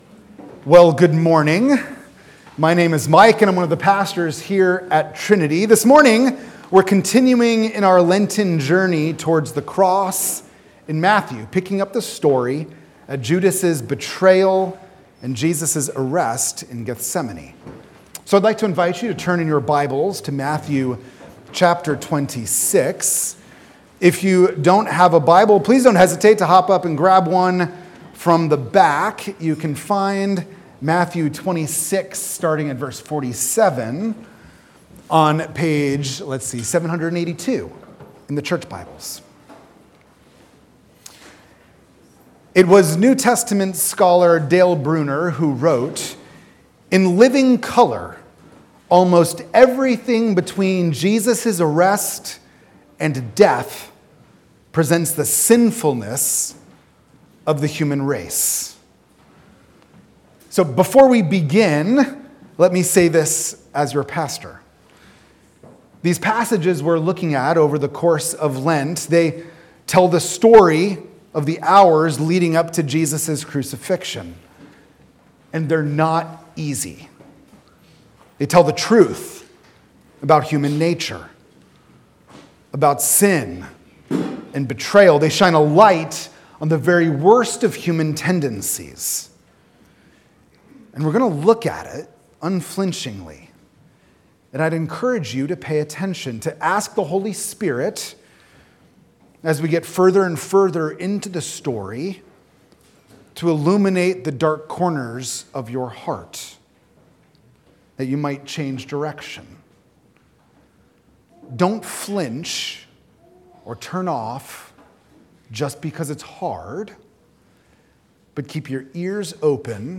Lenten sermon